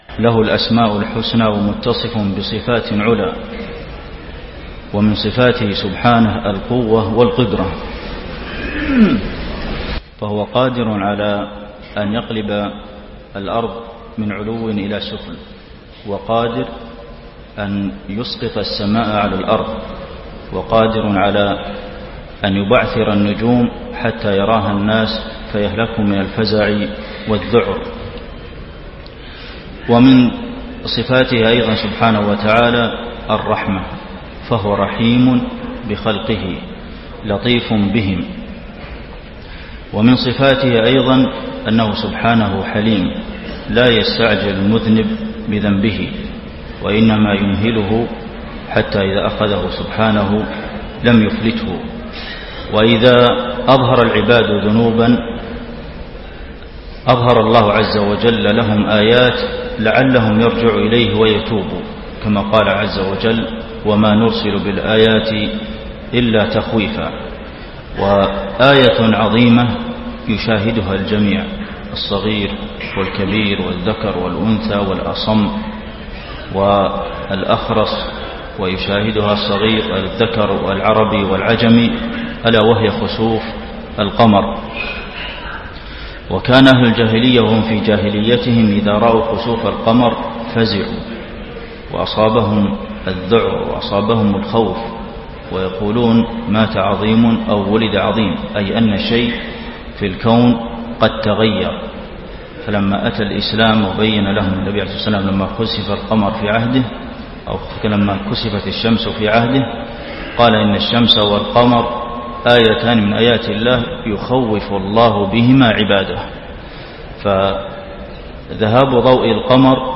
خطبة الخسوف المدينة - الشيخ عبدالمحسن القاسم
تاريخ النشر ١٥ محرم ١٤٣٣ هـ المكان: المسجد النبوي الشيخ: فضيلة الشيخ د. عبدالمحسن بن محمد القاسم فضيلة الشيخ د. عبدالمحسن بن محمد القاسم خطبة الخسوف المدينة - الشيخ عبدالمحسن القاسم